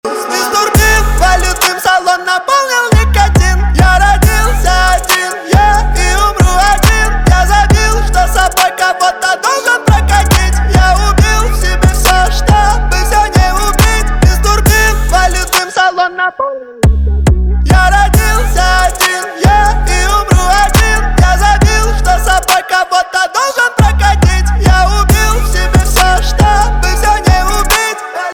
• Качество: 192, Stereo
мужской голос
громкие
русский рэп
качающие